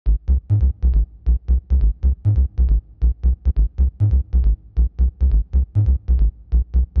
• techno bass alabam punchy G# - 137.wav
techno_bass_alabam_punchy_G_sharp__-_137_fnd.wav